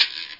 Download Guiro sound effect for video, games and apps.
Guiro Sound Effect
guiro.mp3